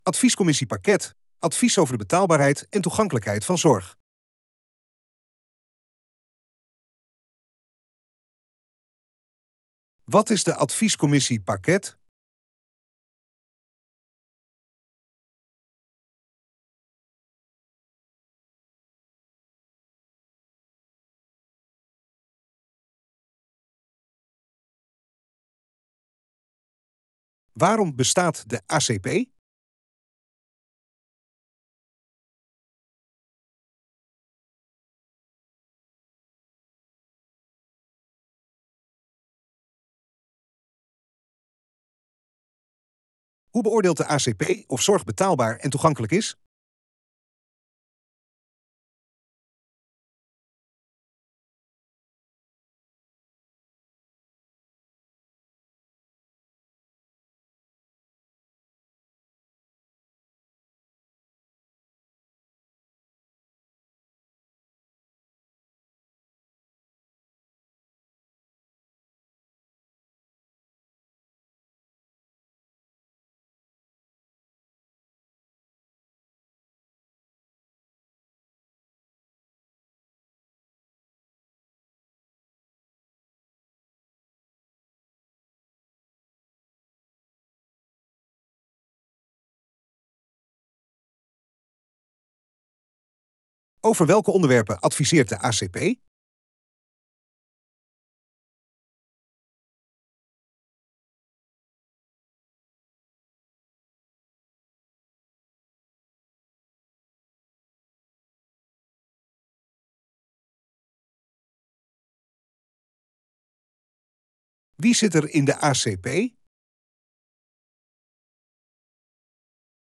Korte uitleg over de ACP